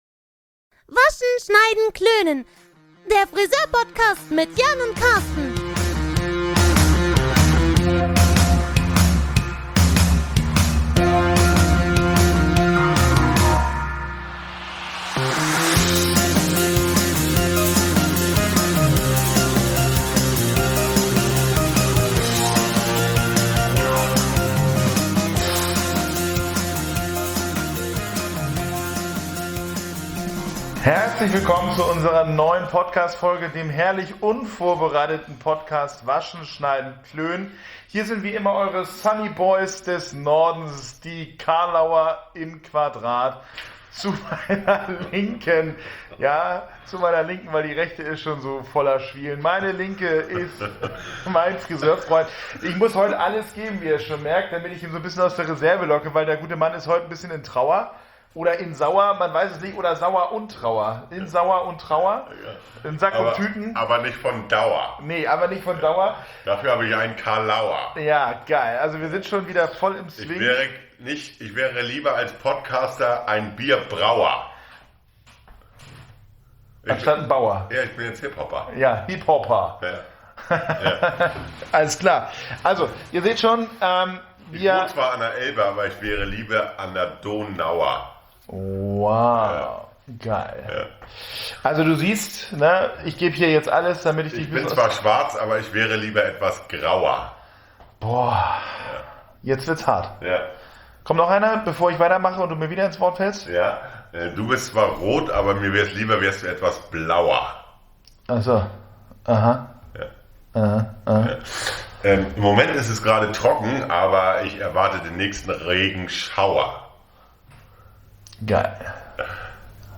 Er ist wieder da: Der herrlich unvorbereitete und offene Podcast!